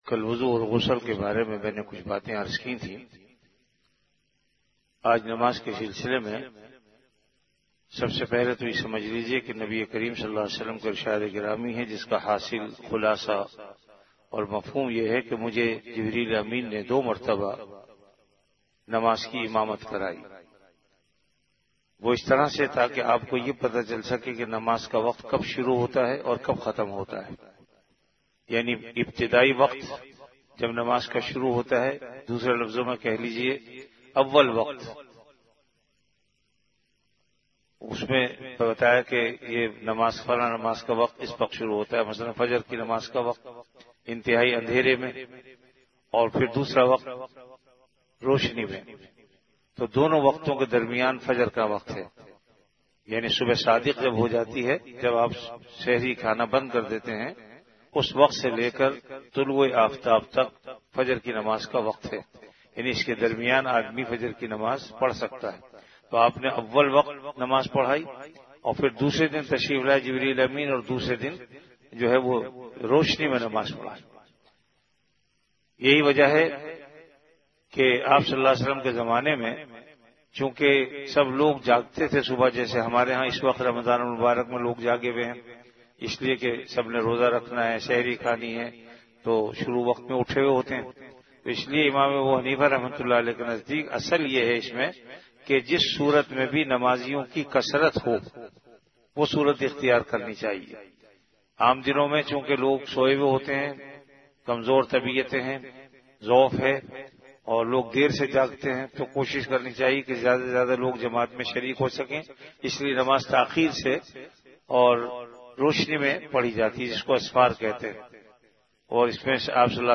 Ramadan - Dars-e-Hadees · Jamia Masjid Bait-ul-Mukkaram, Karachi